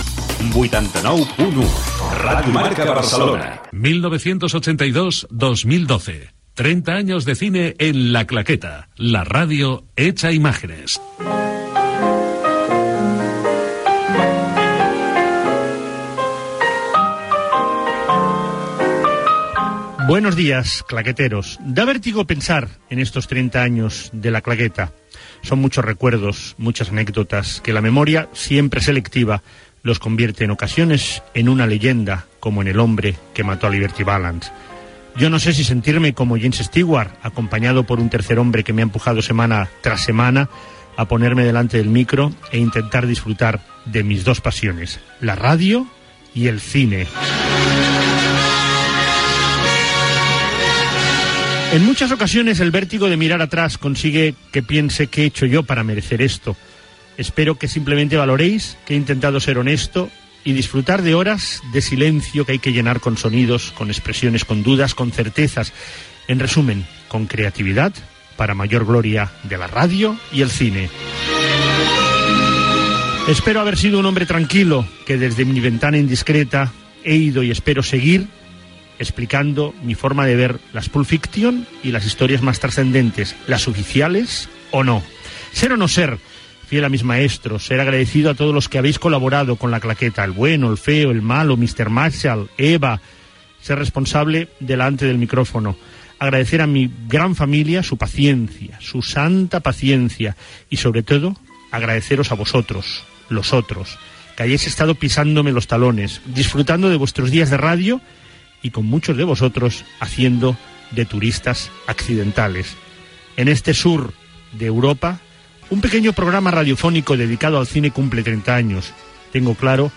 Indicatiu de l'emissora, commemoració dels 30 anys del programa feta des del Cine Verdi de Barcelona. Agraïments inicials als qui han fet possible complir 30 anys.